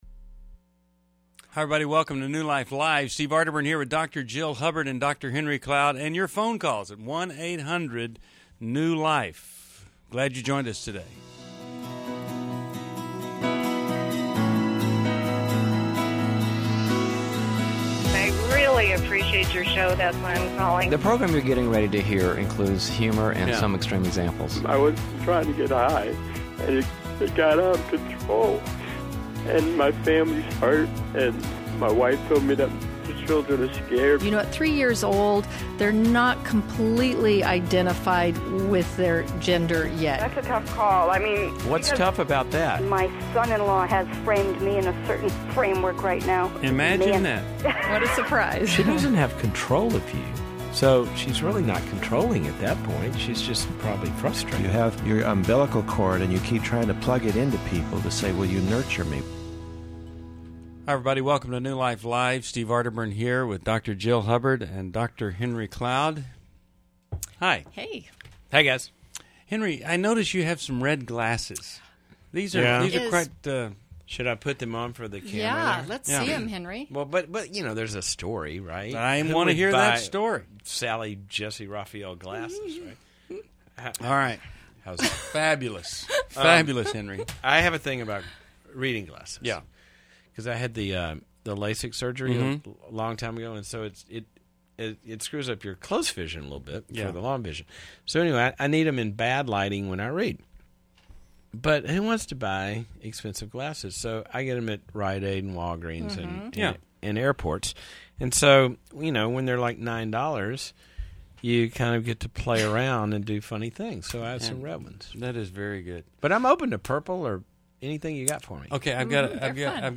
Caller Questions: 1. Should I reconcile with my ex who is a pastor? 2.